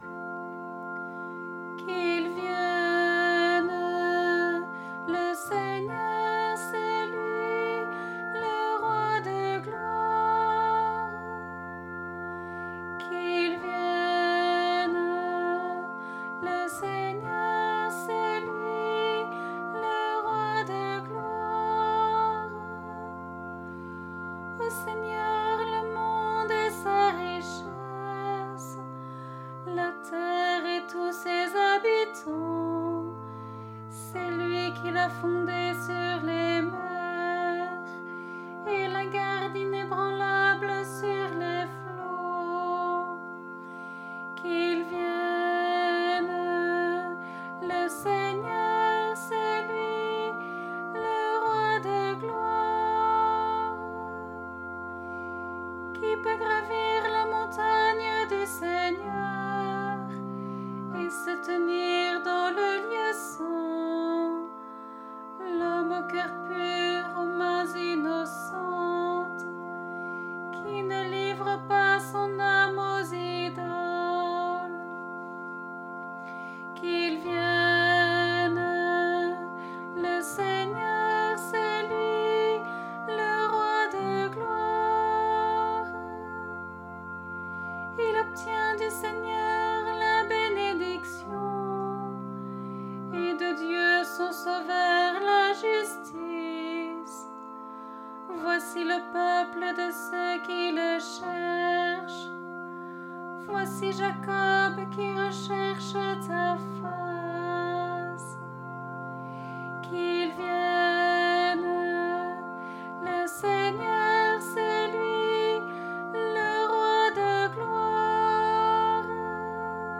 Antienne pour le 4ème dimanche de l’Avent (année A)